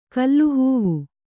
♪ kallu hūvu